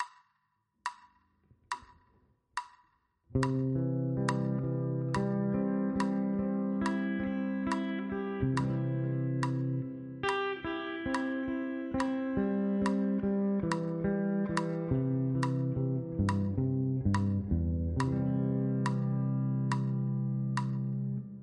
Ex 1 – C-Dur Arpeggio